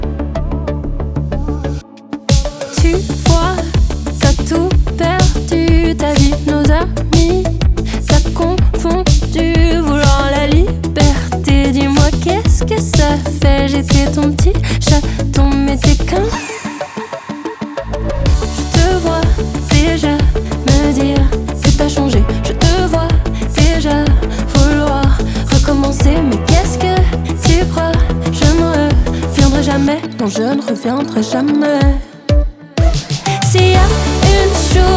Жанр: Поп музыка
French Pop